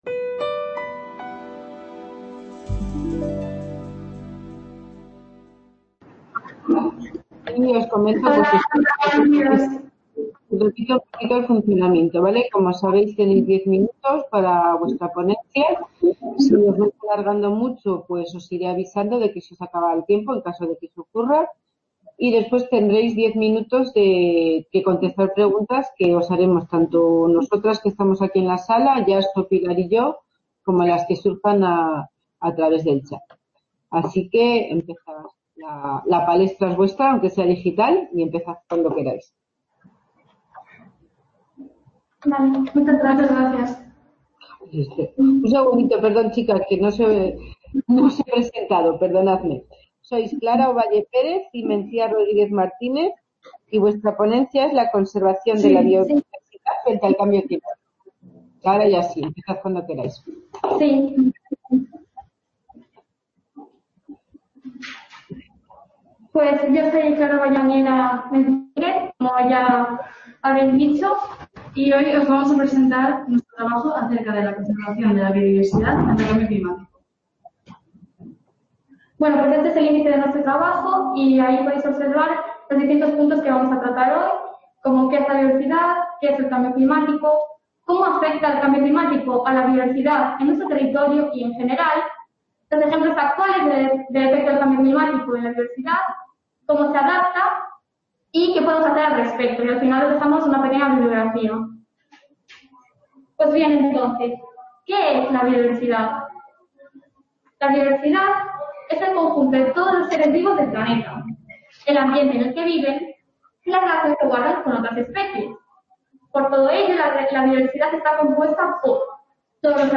Description Congreso organizado por La Fábrica de Luz. Museo de la Energía junto con la ULE, la UNED y en colaboración con FECYT que se desarrolla en 3 salas CA Ponferrada - 2 Edición Congreso de Jóvenes Expertos.